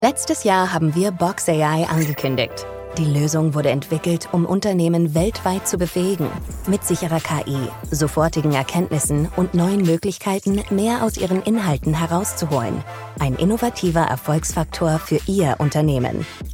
Vidéos explicatives
Microphone : Sennheiser 416, Rode NT-1, Apogee Hype Mic
Cabine : StudioBricks